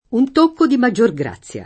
un t1kko di maJJor gr#ZZLa] (Valgimigli) — stessa pn. dell’-o- tonico in rintocco e ritocco